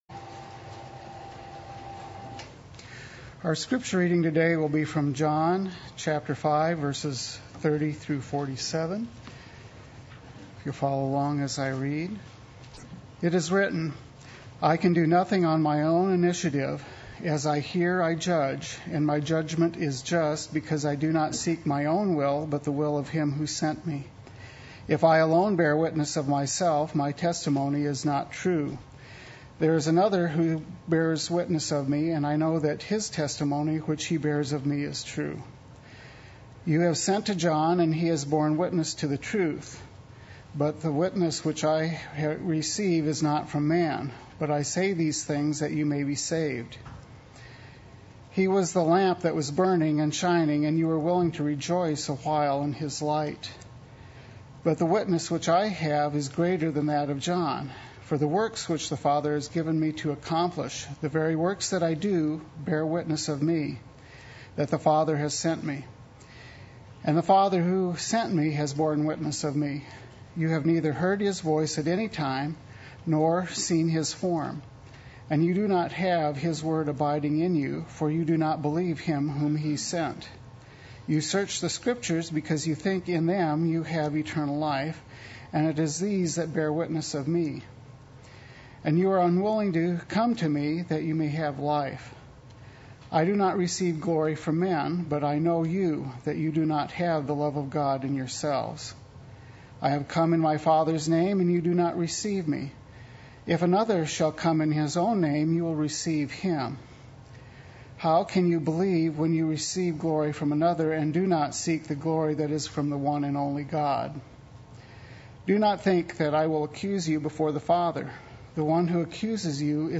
Play Sermon Get HCF Teaching Automatically.
It is These That Bear Witness of Me Sunday Worship